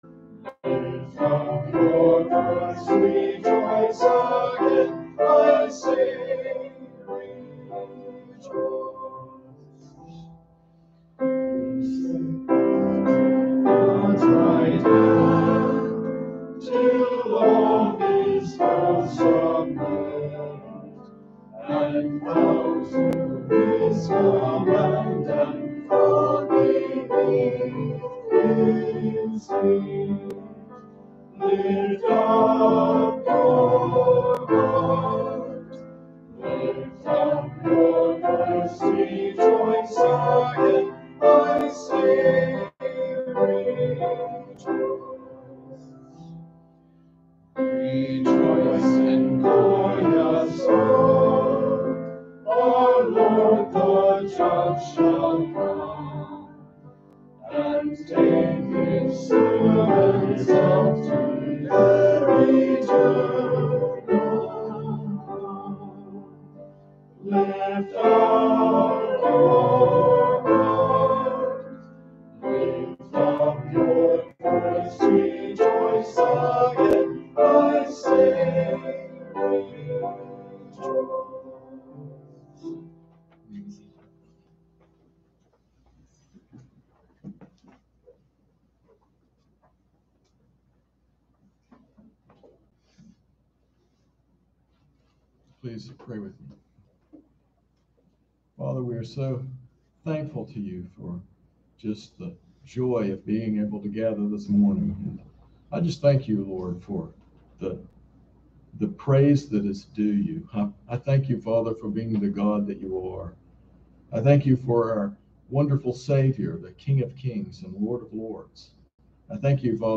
sermon-10-3-21.mp3